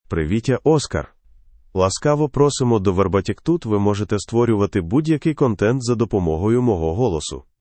Oscar — Male Ukrainian AI voice
Oscar is a male AI voice for Ukrainian (Ukraine).
Voice sample
Listen to Oscar's male Ukrainian voice.
Male
Oscar delivers clear pronunciation with authentic Ukraine Ukrainian intonation, making your content sound professionally produced.